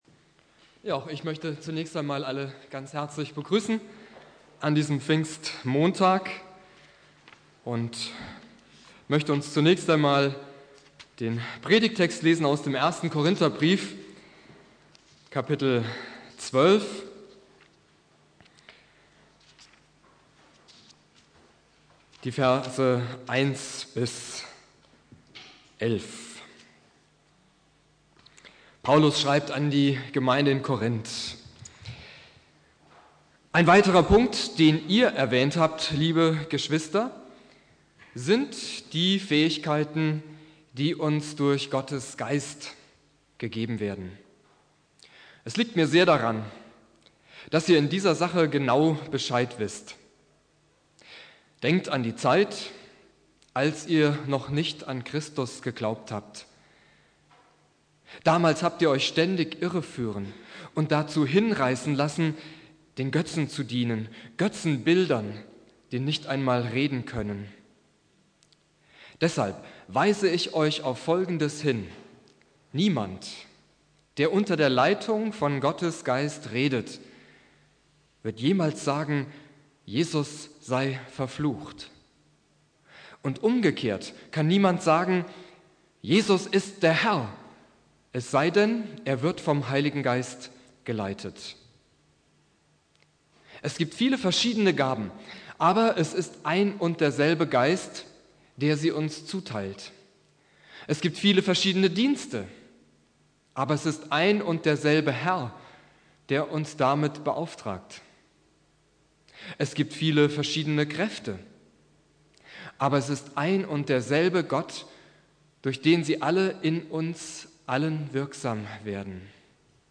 Predigt
Pfingstmontag Prediger